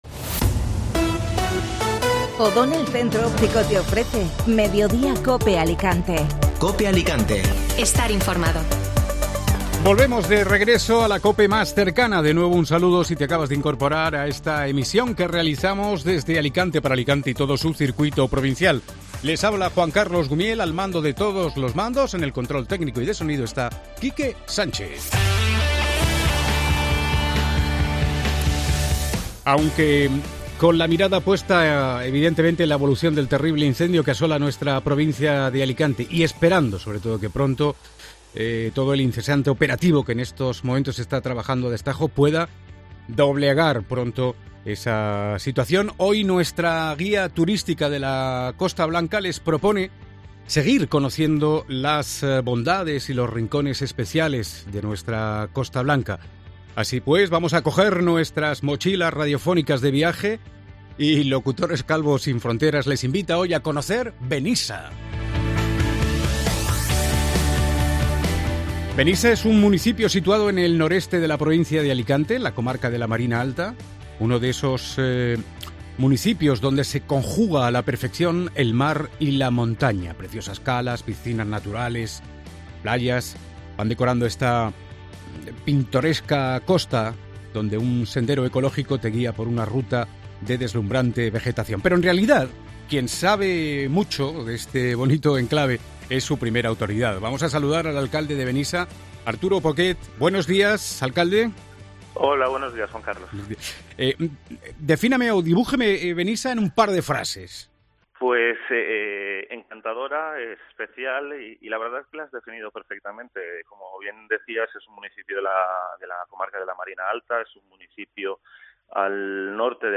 Hoy la Guía Turística de la Costa Blanca de Mediodía COPE nos lleva a Benissa. No te pierdas la entrevista a su alcalde, Arturo Poquet.